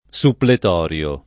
suppletorio [ S upplet 0 r L o ]